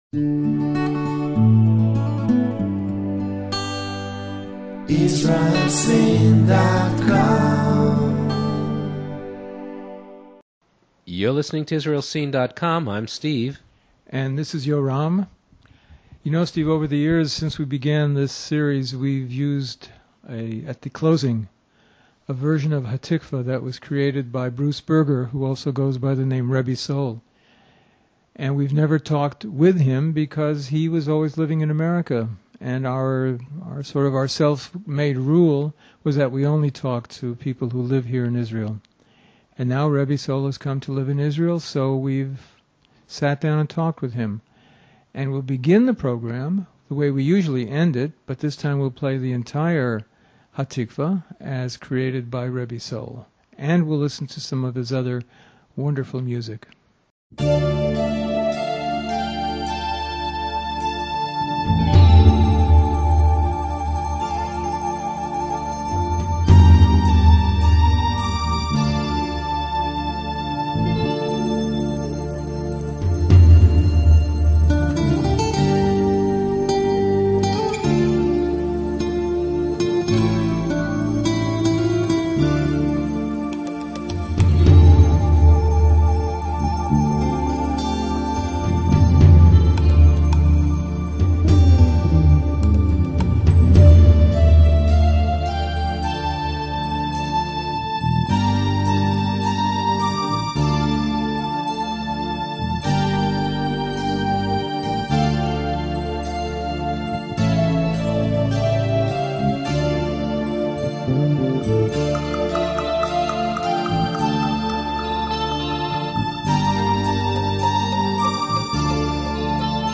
He shares his music, ideas and vision in this interview